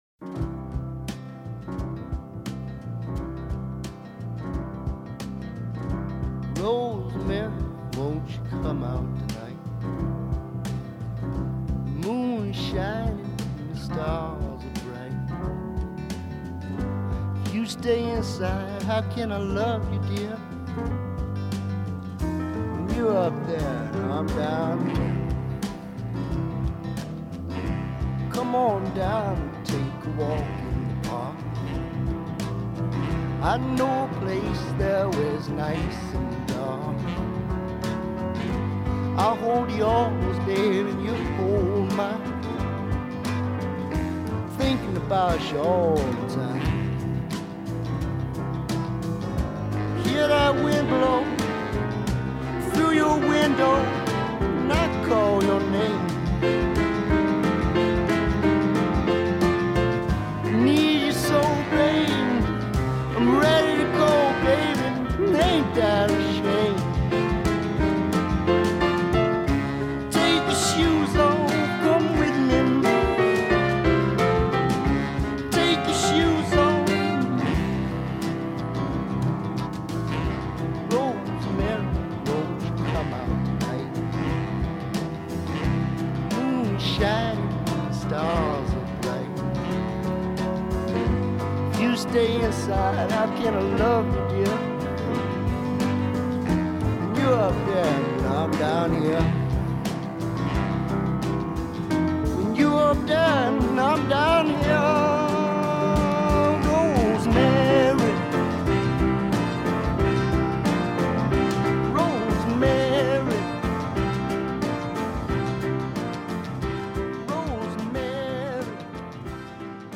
Deeply felt, earnest love songs sit next to scathing
a subtle, quiet record
piano